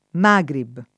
Magrib [ m #g rib ]